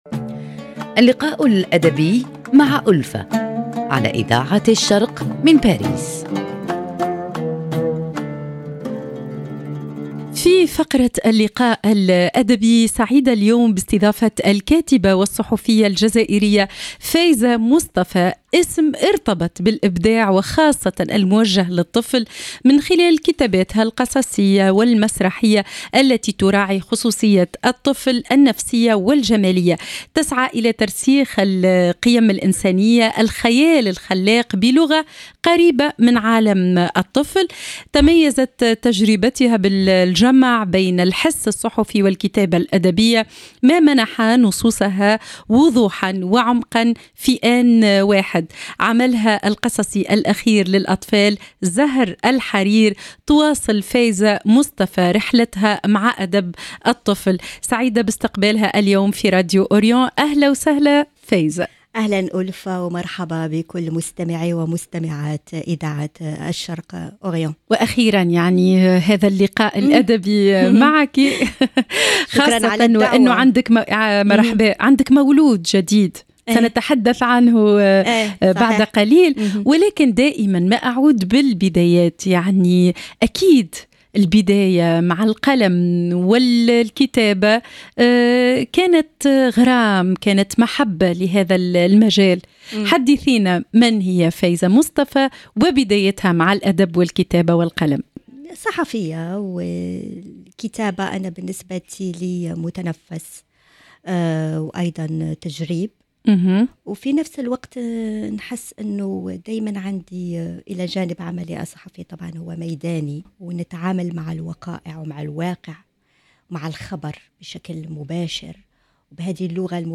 في فقرة اللقاء الادبي نستضيف الكاتبة والصحفية الجزائرية